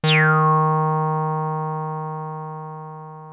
303 D#3 9.wav